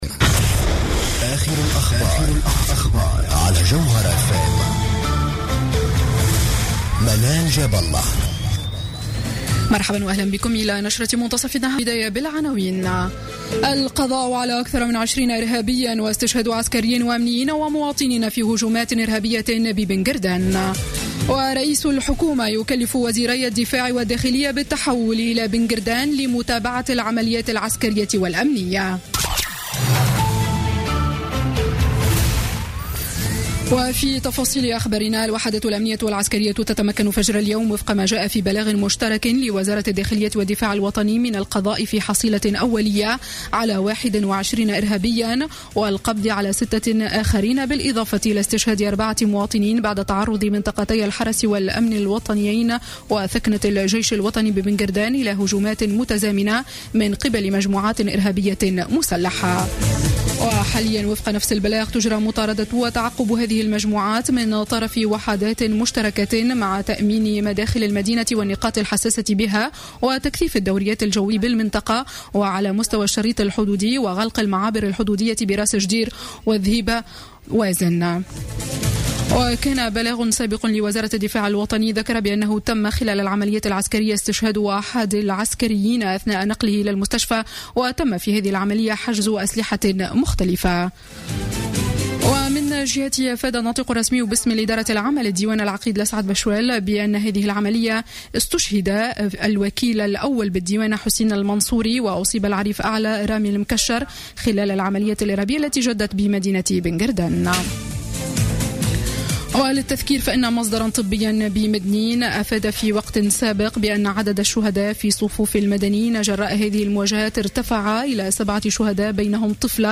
نشرة أخبار منتصف النهار ليوم الاثنين 7 مارس 2016